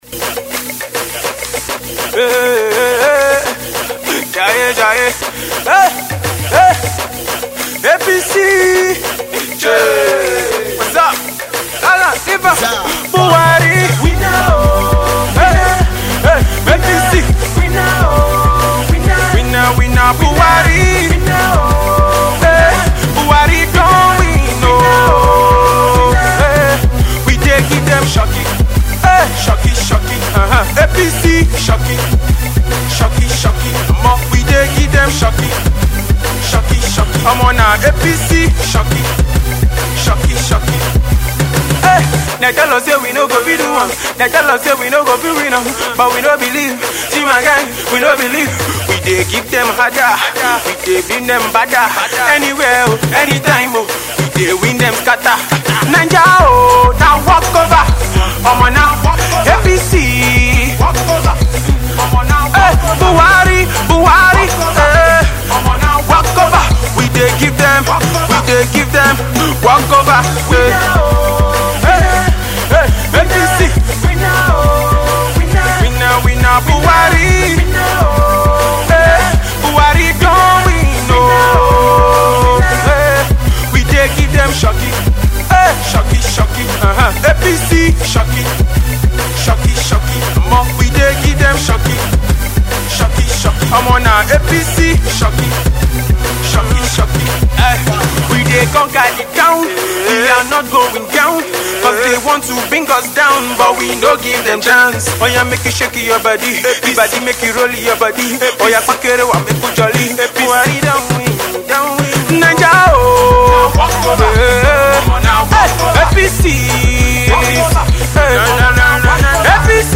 Not only isnt the track well produced, it sounds rushed